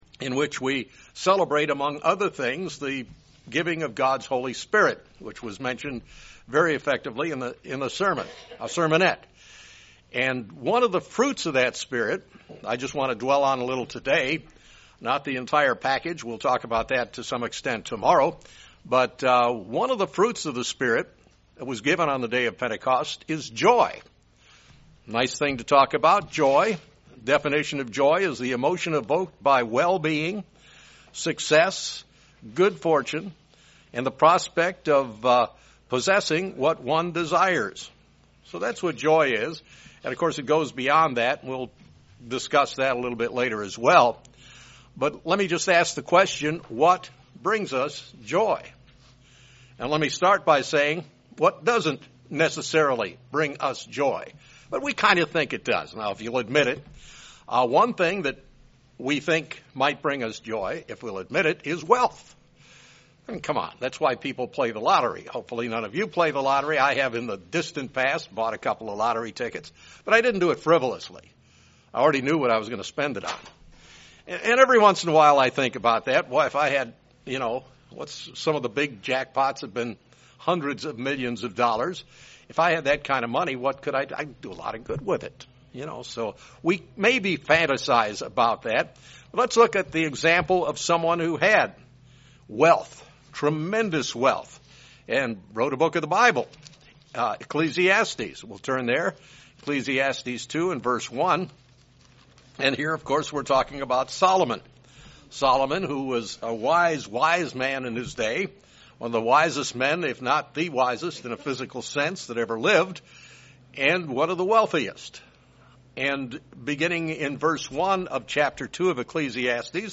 As we approach the Feast of Pentecost, this sermon looks into the topic of Joy, one of the fruit of the Spirit mentioned in Galatians. The speaker jumps into the topic of Joy, what it is and what it is not.